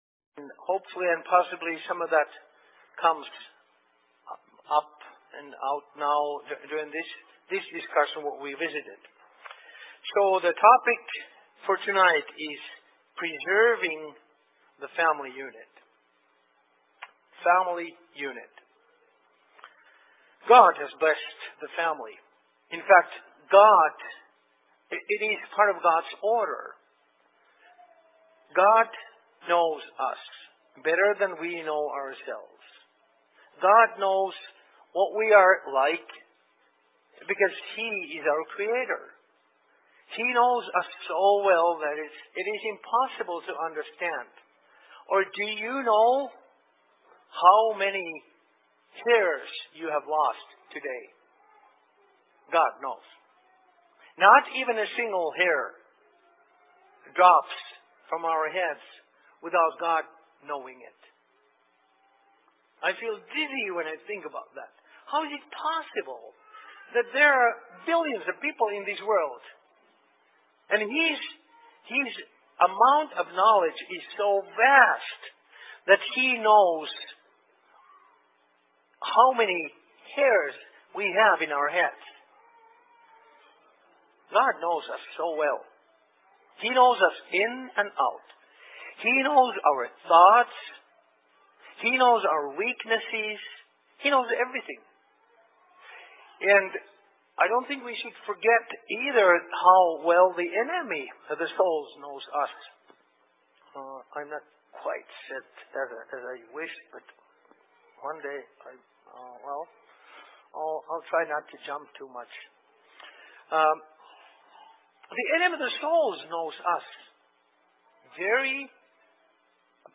Discussion Evening/Presentation in Menahga 18.02.2017
Location: LLC Menahga